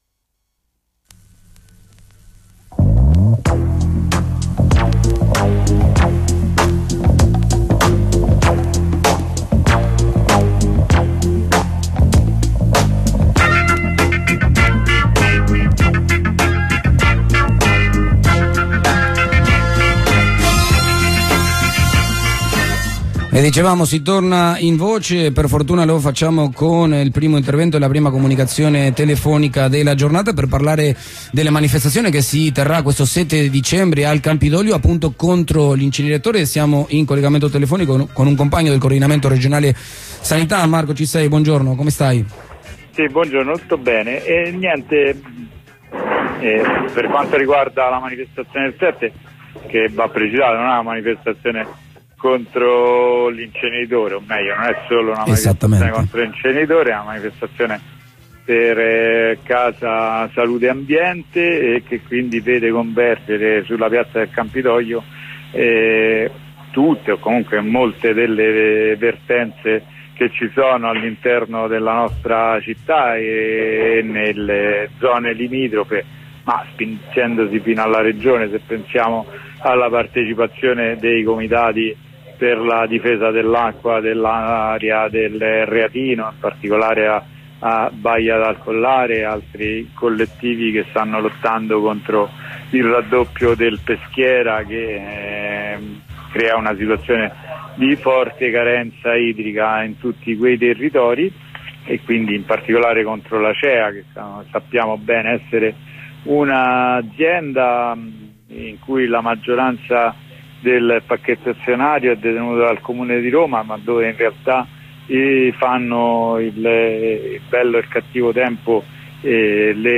Comunicazione telefonica con il Coordinamento Regionale Sanità